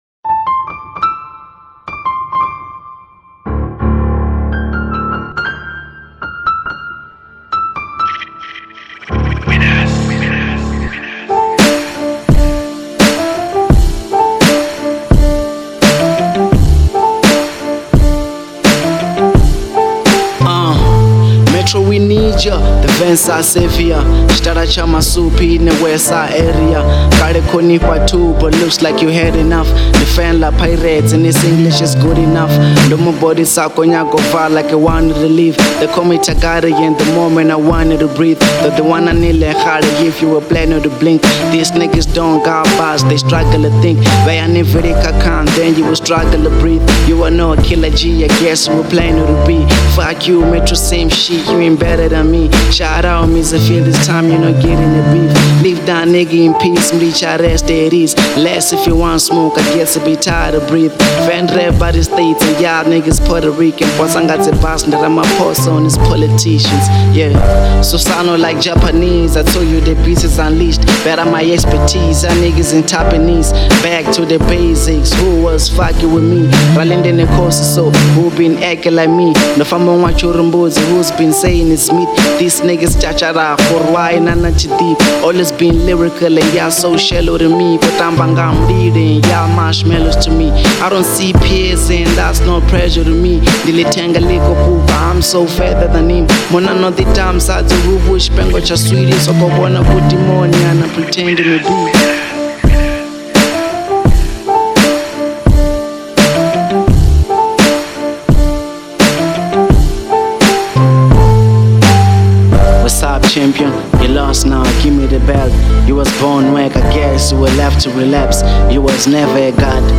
02:51 Genre : Hip Hop Size